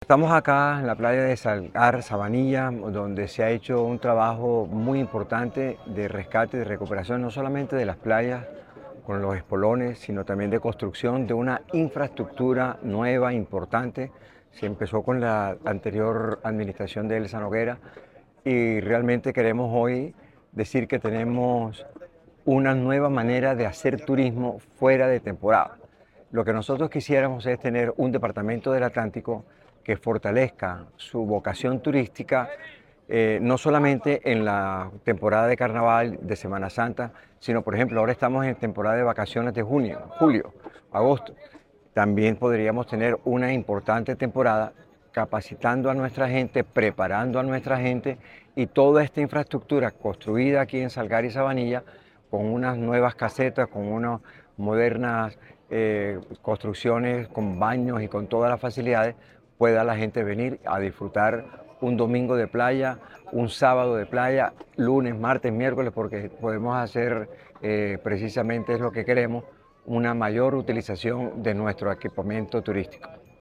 Gobernador Eduardo Verano
Audio-Eduardo-Verano-recorrido-playa-Sabanilla-sector-Country.mp3